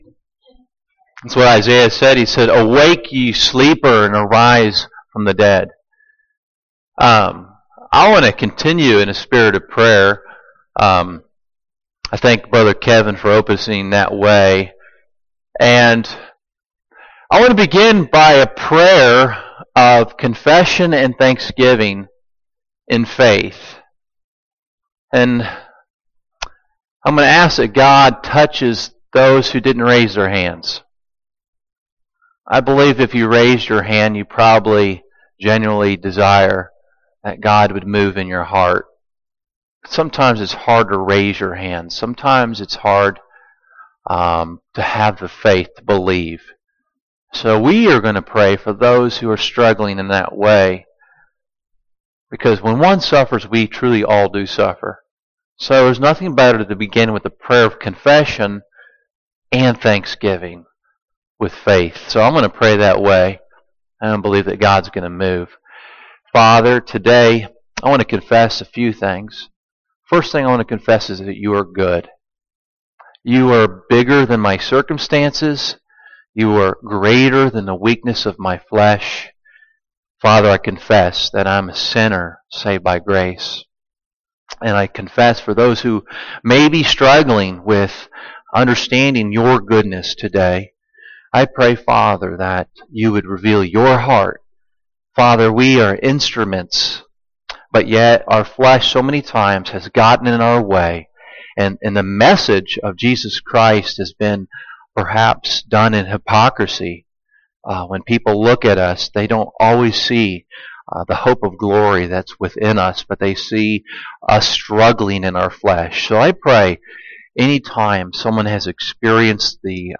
Service Type: Sunday Morning Topics: repentance « Is the Coming of Our Lord ar Hand?